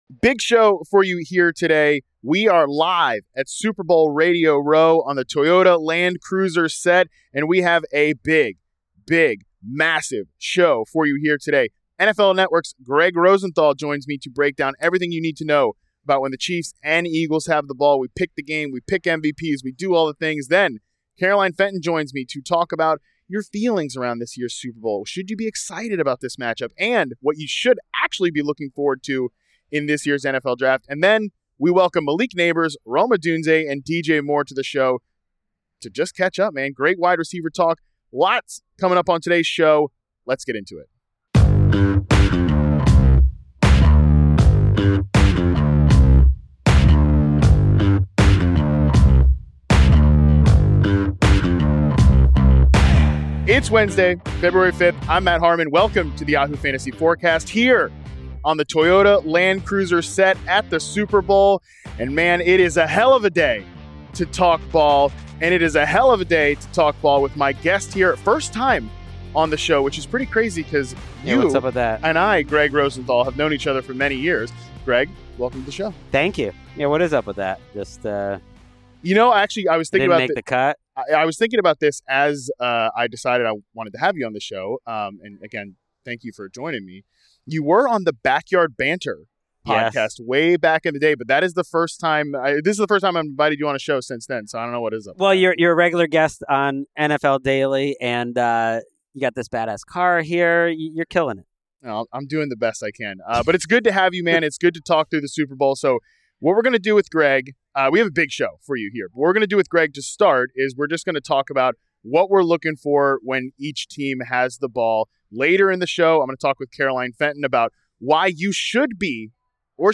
It's a super show for Super Bowl week.